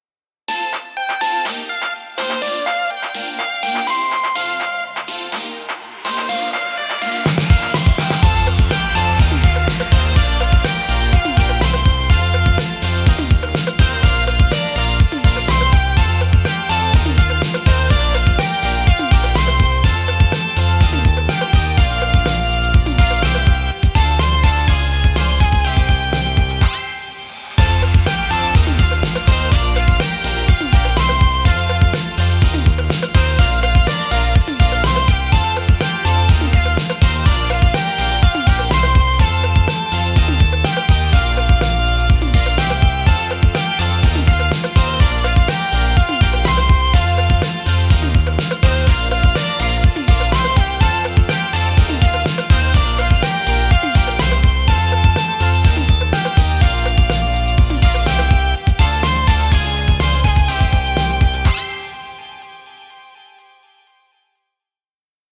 明るい/オープニング/かわいい/コミカル